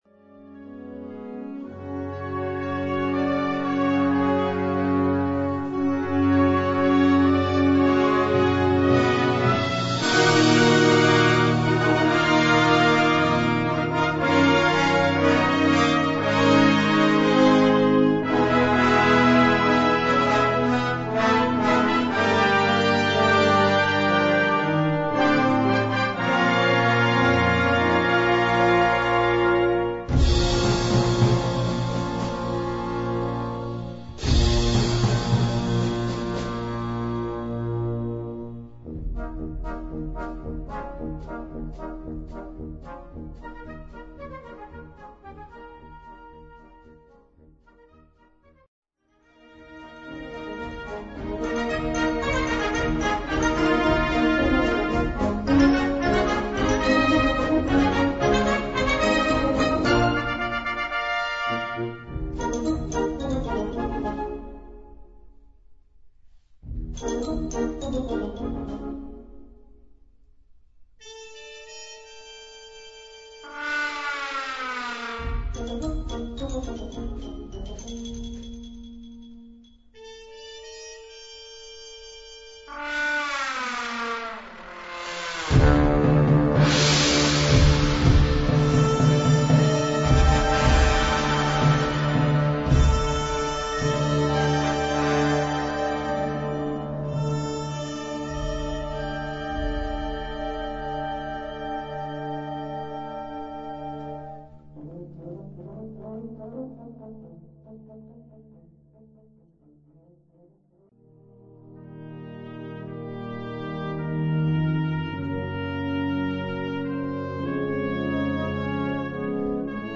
Subcategorie Concertmuziek
Bezetting Ha (harmonieorkest)